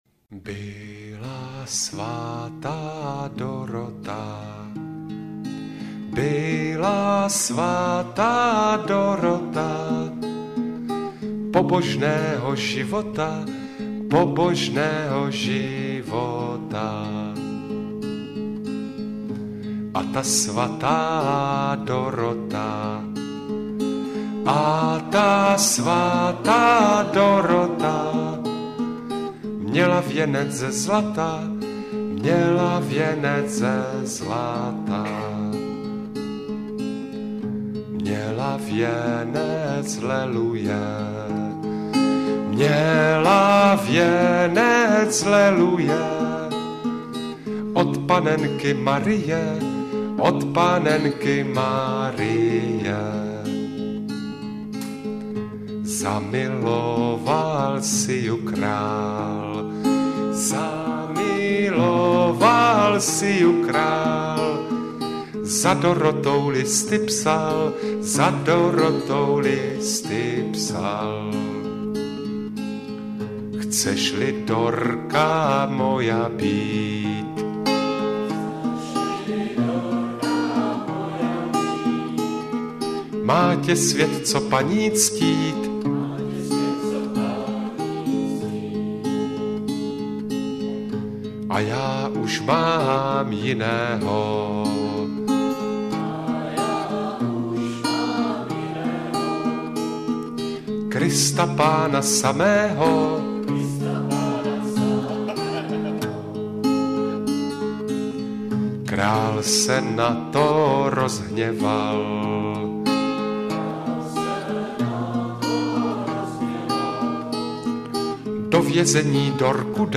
Lidové písně zpívané | Jarek Nohavica